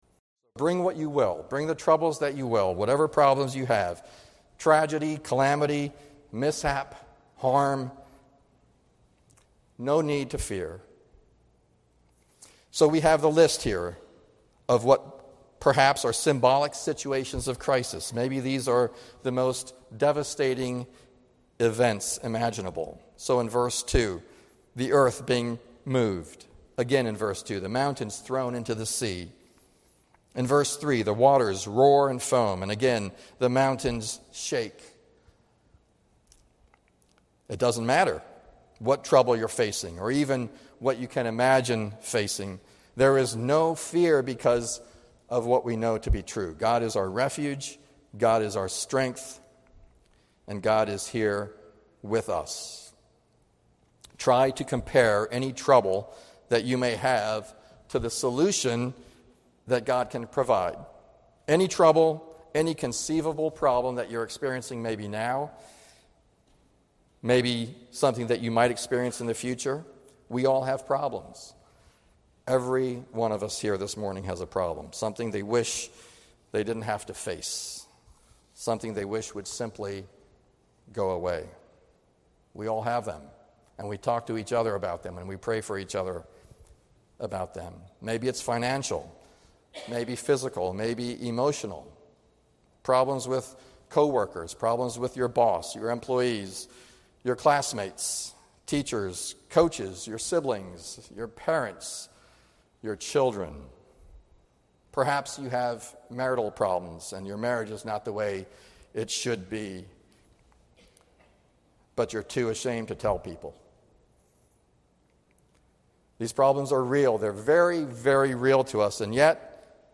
2019 Stay up to date with “ Limerick Chapel Sunday Sermons ”